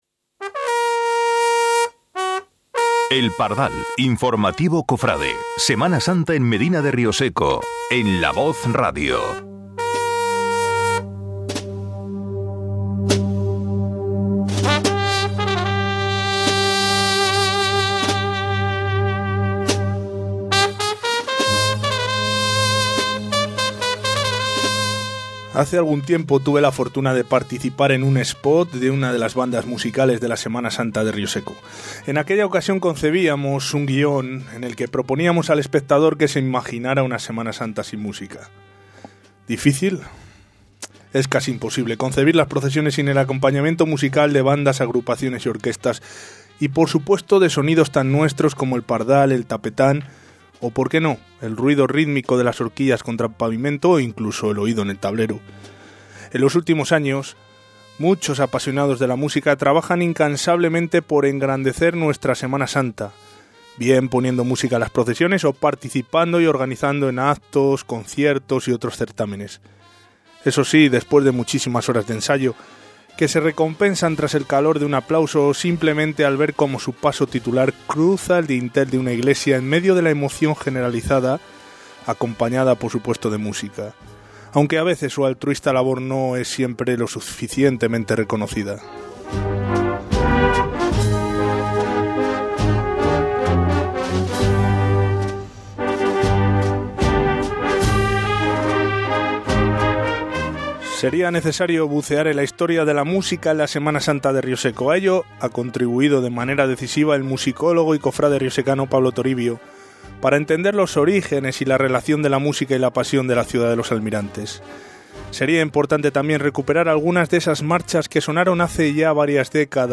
Quinto Pardal de la temporada. Un programa radiofónico muy musical que ha querido pulsar la actualidad de la Semana riosecana a través de algunas de sus bandas y agrupaciones musicales.